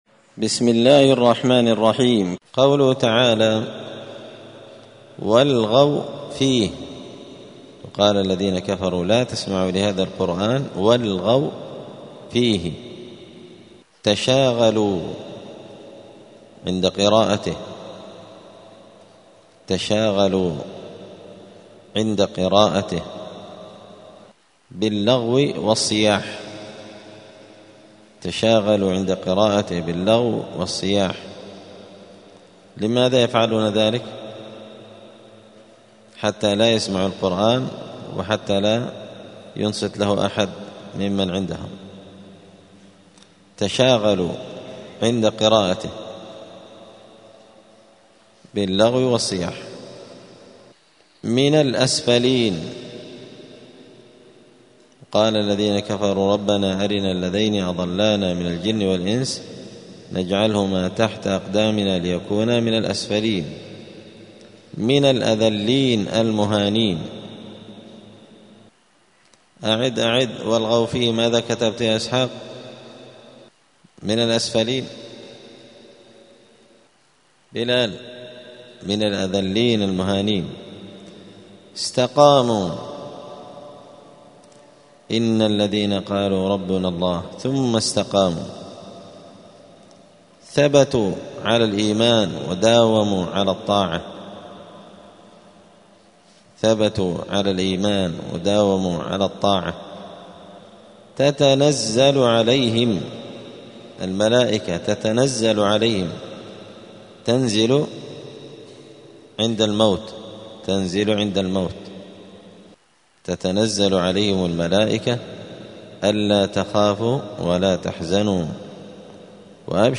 *(جزء فصلت سورة فصلت الدرس 219)*